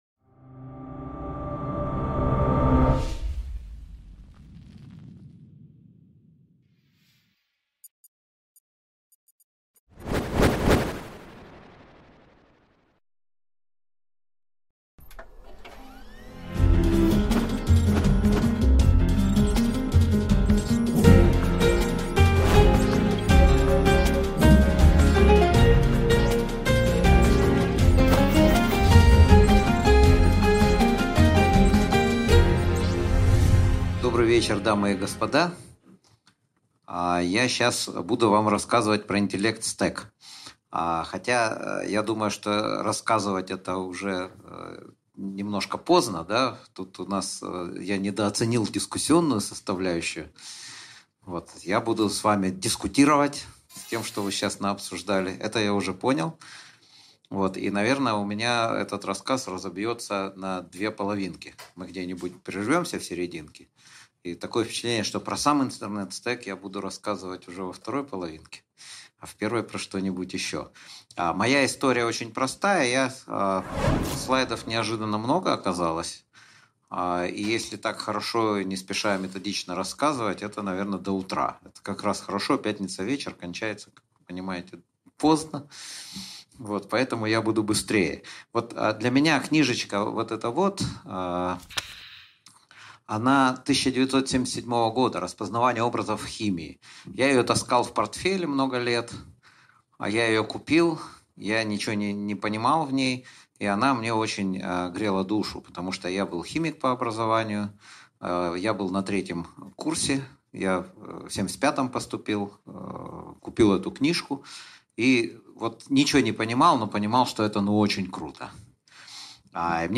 Аудиокнига Интеллект-стек, ч. II. Как создаётся Deus ex machina | Библиотека аудиокниг